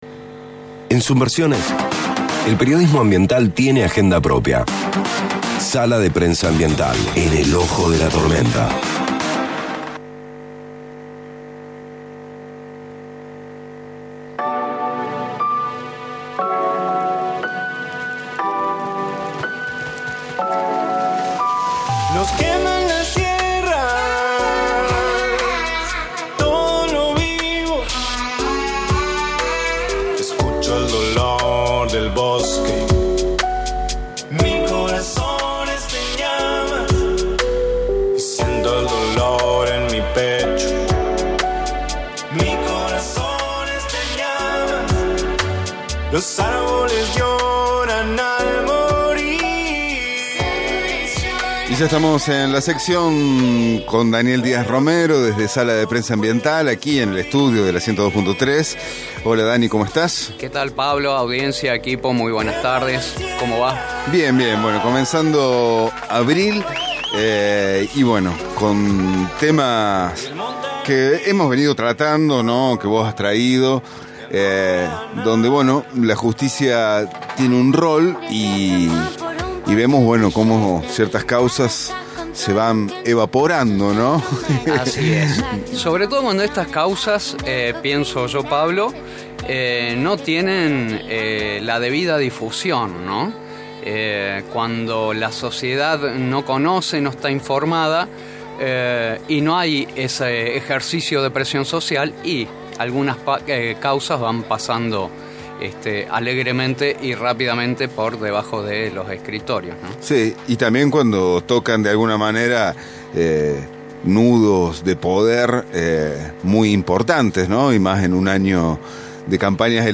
> Escucha la entrevista aquí: Causa por contaminación ambiental de la Planta de Bajo Grande